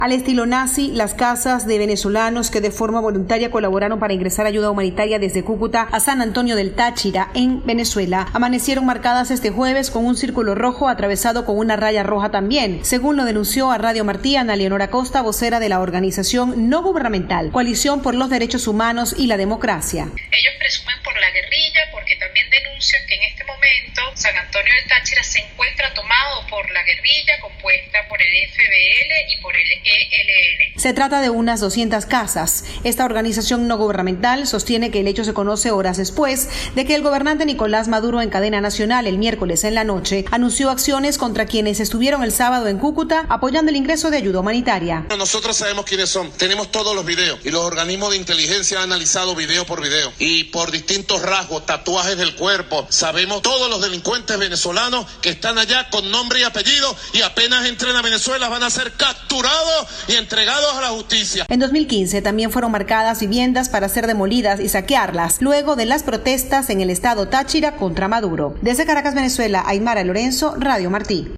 Noticias de Radio Martí
Grupos civilistas en Venezuela denunciaron que el gobierno está acosando a personas que intentaron ingresar ayuda humanitaria al país. Desde Caracas